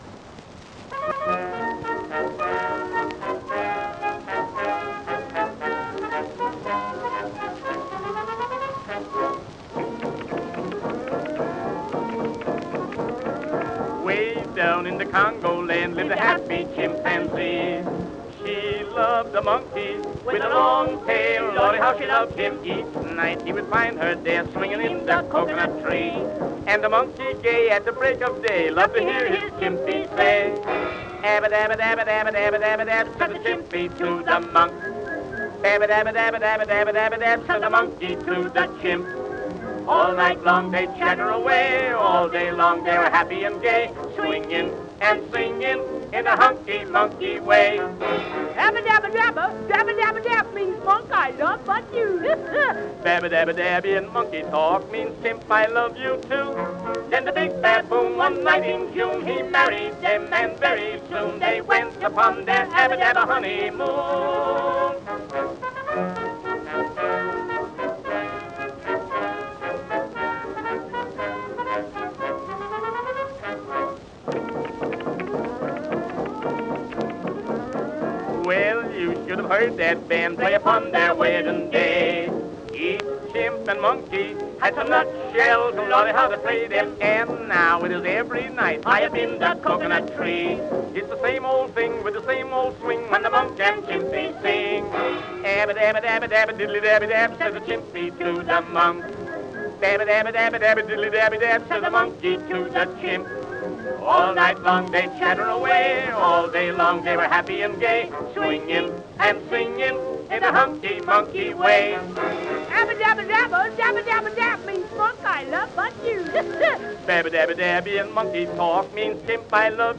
The performers have a good time monkeying around with this song, "The Aba Daba Honeymoon."
We also hear these sentiments in love songs, such as this funny old tune, "Aba Daba Honeymoon."
AUDIO CREDIT: Collins, Arthur and Byron G. Harlan, performers.